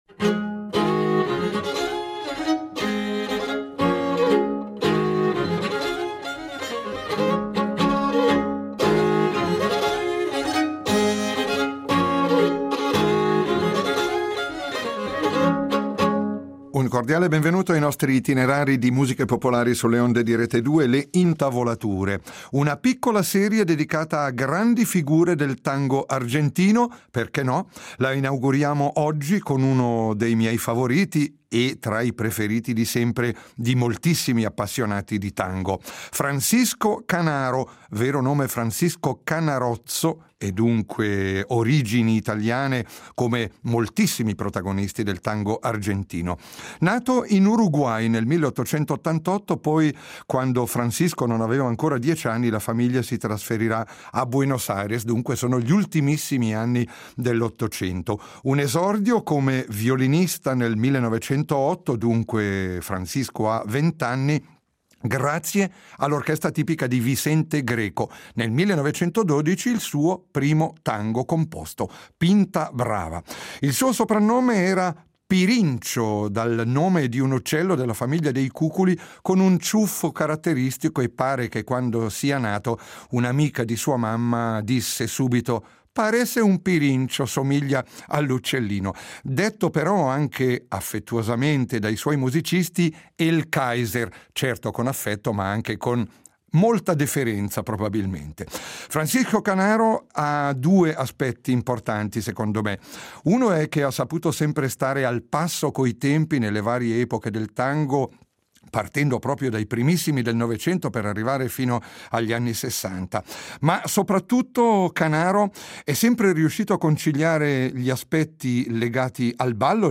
Contenuto audio Disponibile su Scarica Il nostro programma folk inaugura questa settimana una piccola serie di ritratti in musica e (poche) parole di grandi figure del tango argentino: musicisti che hanno segnato la storia di questo genere ormai diventato un fenomeno culturale globale, che affascina milioni di persone in tutto il mondo e che proprio in questi ultimi anni ha avuto un’espansione importante… Cominciamo con Francisco Canaro , detto “Pirincho”, violinista, compositore, promotore e direttore d’orchestra che ha vissuto varie epoche del tango, riuscendo ad essere protagonista di primo piano in una carriera durata oltre cinquant’anni, sempre con grande qualità e notevole successo.